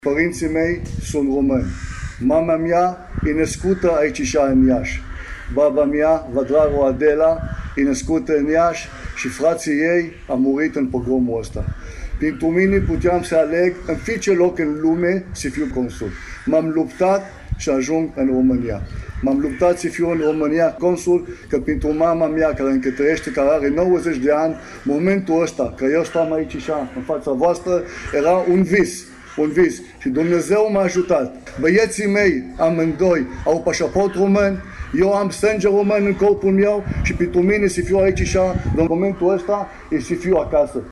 La Cimitirul Evreiesc, a avut loc o manifestare în cadrul căreia au fost rememorate evenimentele triste petrecute în urmă cu 83 de ani, dar și un moment simbolic de recunoaştere a meritelor deosebite ale comunităţii evreieşti în dezvoltarea Iaşiului.